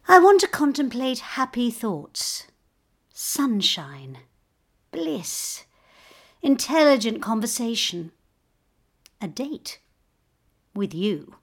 ‘Touch’ and listen to the emotions and thoughts of this heartbroken woman.